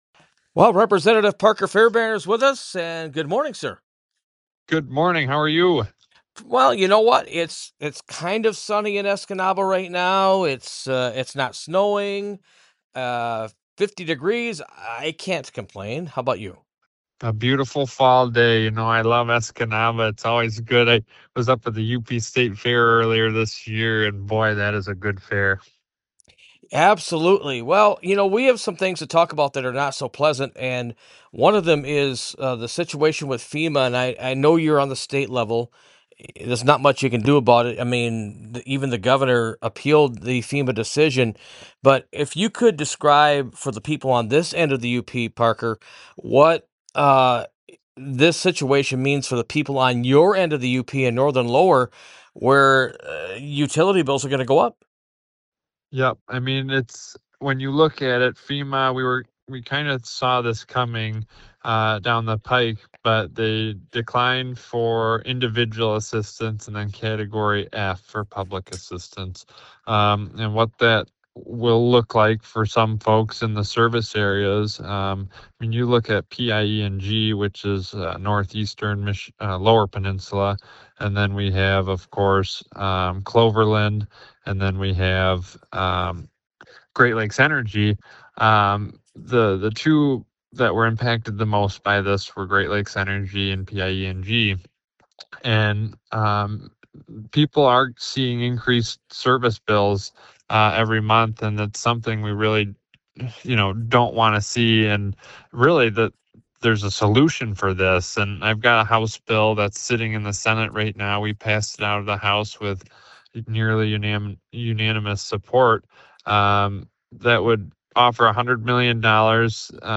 And he talked about his relationship with the U.P.’s three state representatives and state senator, even though he lives below the Bridge and represents only a sliver of the Eastern U.P. The full interview is posted above.